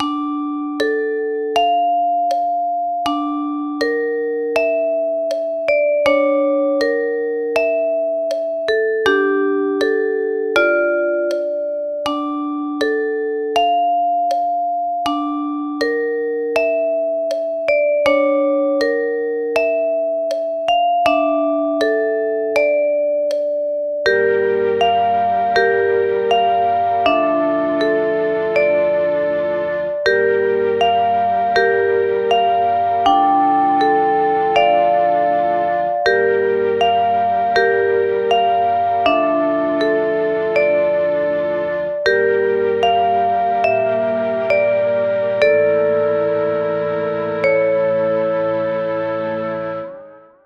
暗い楽曲
【イメージ】ホラー、静寂、時計の音が鳴り響く部屋 など